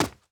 Player_Footstep_05.wav